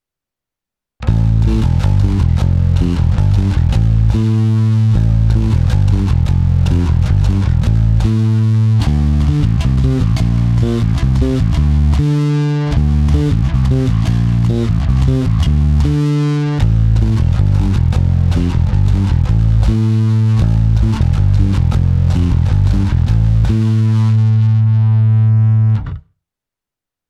Nahrávku jsem prohnal multiefektem Hotone Ampero, ale v něm mám zapnutou prakticky jen drobnou ekvalizaci a hlavně kompresor.
Oba snímače zkreslení - basy +50%, středy +50%, výšky +50%